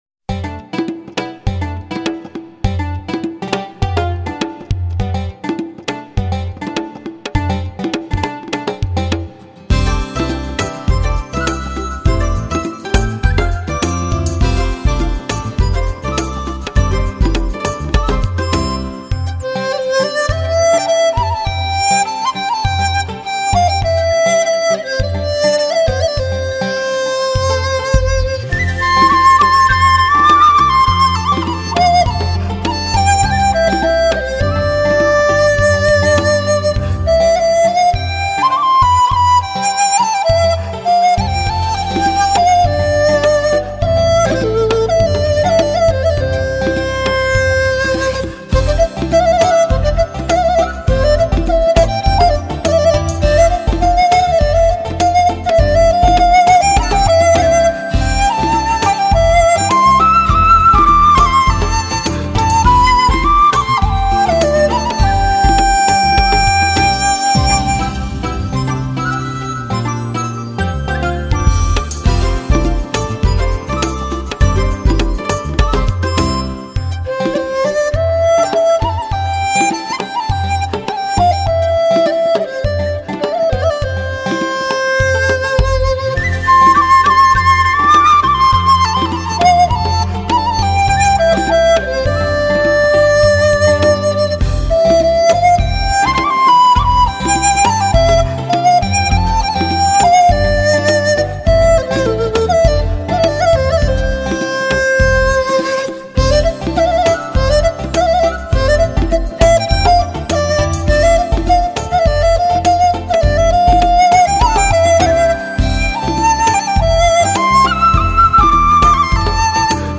瑰丽绮美,空灵幽幻的中国风情美乐集
唱片类型：民族音乐
专辑语种：纯音乐